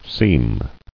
[seem]